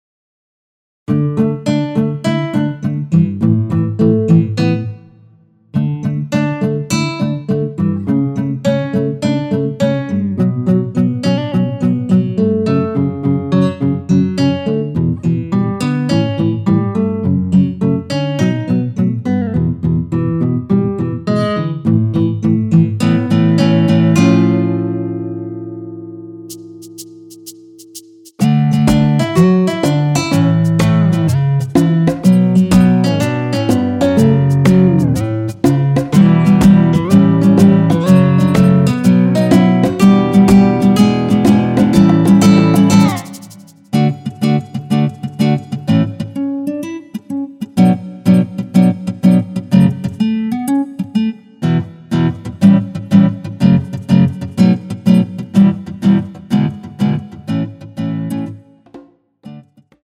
전주 없이 시작 하는곡이라 노래 하시기 편하게 전주 2마디 많들어 놓았습니다.(미리듣기 확인)
원키에서(-2)내린 MR입니다.
앞부분30초, 뒷부분30초씩 편집해서 올려 드리고 있습니다.